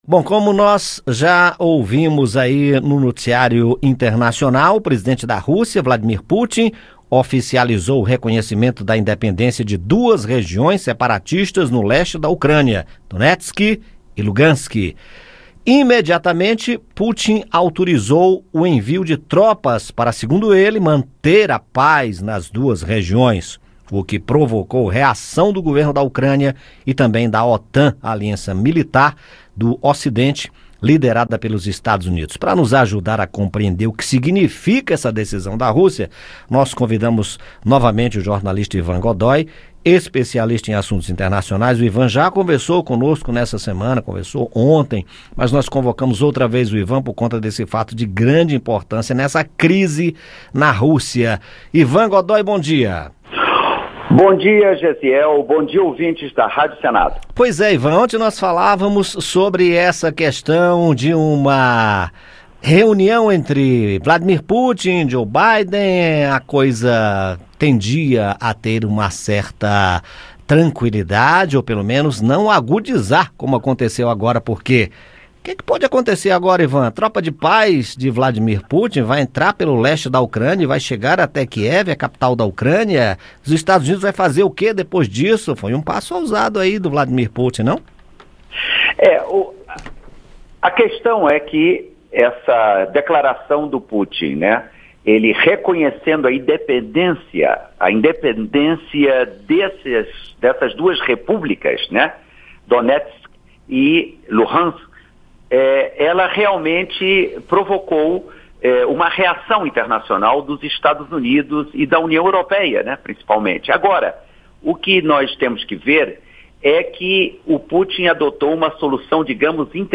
O jornalista e especialista em assuntos internacionais